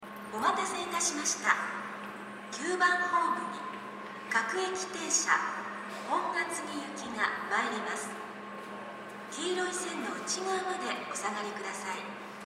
９番ホーム各駅停車区間準急
接近放送各駅停車　本厚木行き接近放送です。
スピーカーも設置されていますがこちらは高くはないですね。